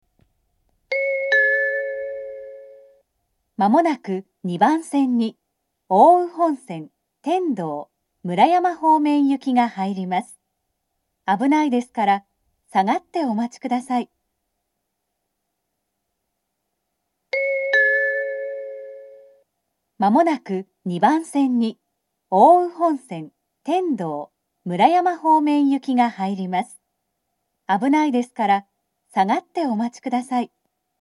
２番線下り接近放送